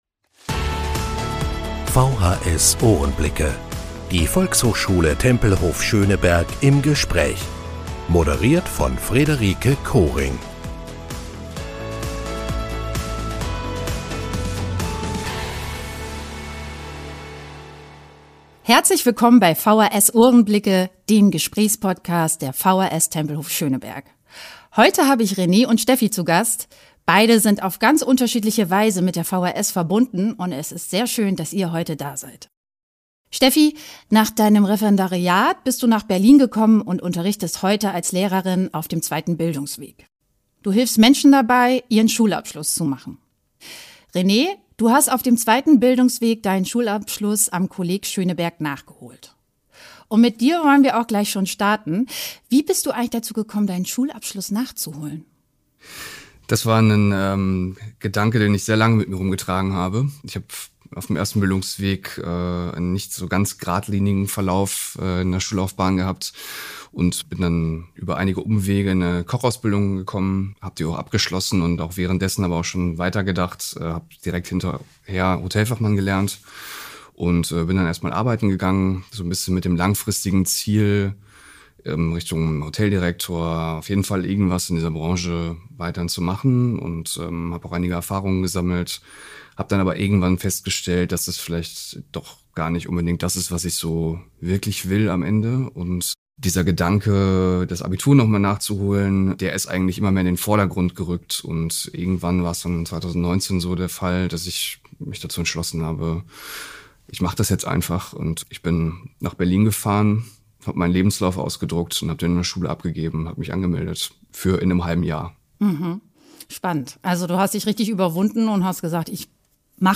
Podcast zum Thema zweiter Bildungsweg Video kann wegen fehlender Software nicht abgespielt werden.